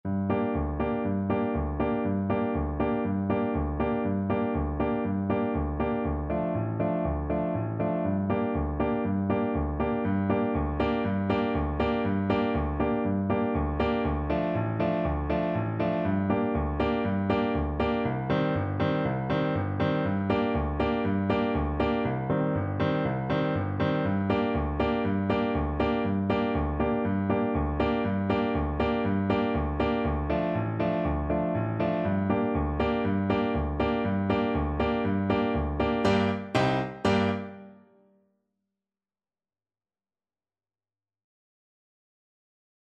4/4 (View more 4/4 Music)
Allegro vivo (View more music marked Allegro)